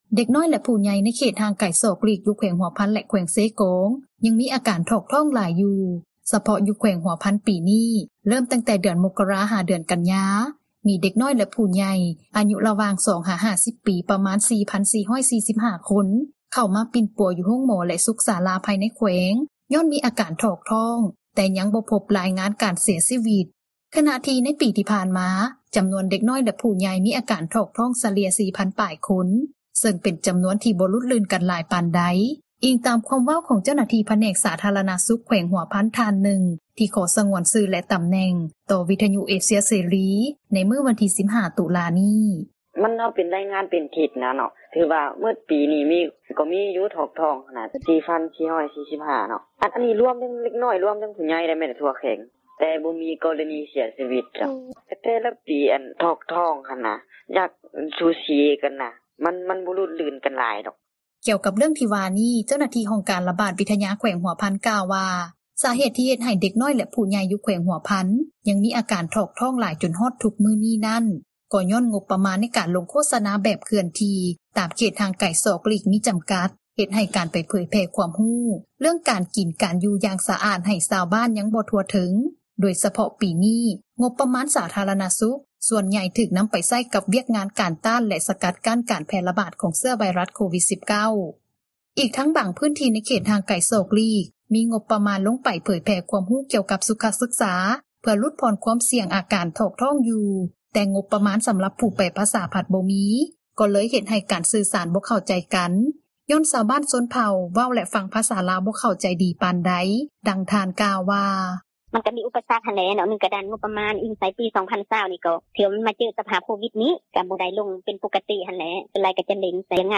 ເດັກນ້ອຍແລະຜູ້ໃຫຍ່ ຖອກທ້ອງ — ຂ່າວລາວ ວິທຍຸເອເຊັຽເສຣີ ພາສາລາວ